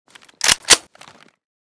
vityaz_unjam.ogg